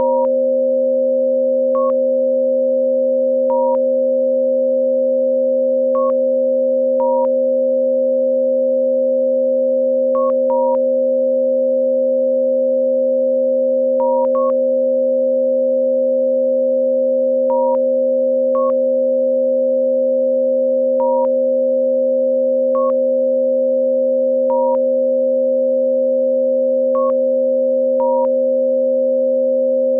yard2yield_528hz_spagardenbirds.mp3